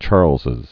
(chärlzĭz)